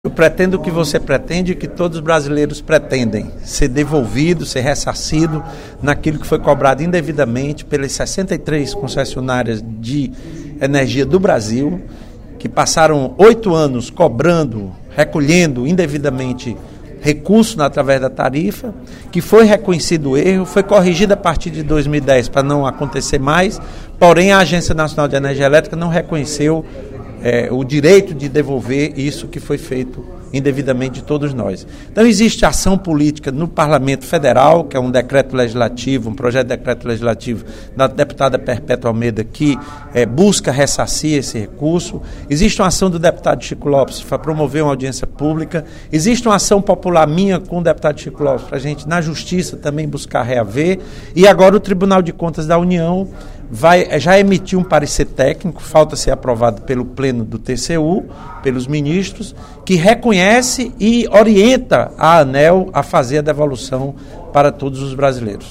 O presidente do Conselho de Altos Estudos e Assuntos Estratégicos da Assembleia Legislativa, deputado Lula Morais (PCdoB), disse, na sessão plenária desta terça-feira (08/05), que as 63 concessionárias de energia elétrica do Brasil devem ressarcir a população de um prejuízo de R$ 8 bilhões.